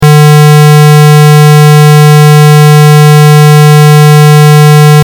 Die Frequenz der Audiodatei beträgt also 150 Hz.
150hz.mp3